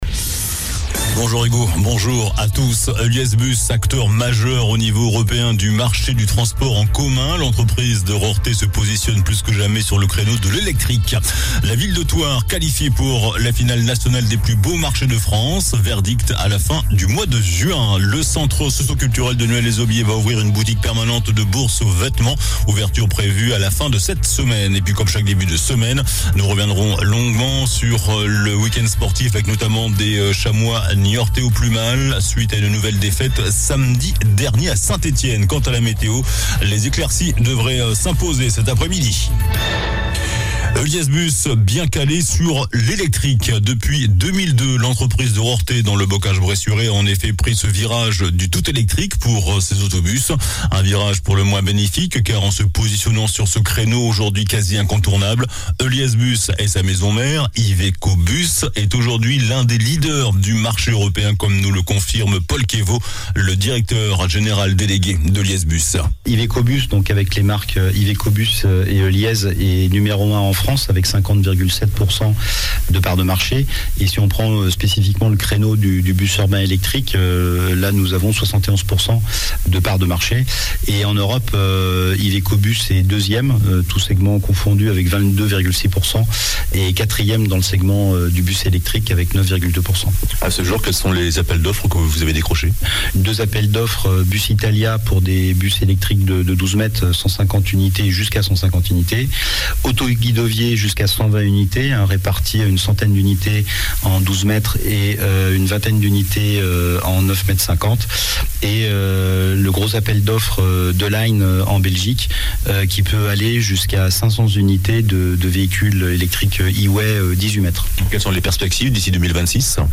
JOURNAL DU LUNDI 03 AVRIL ( MIDI )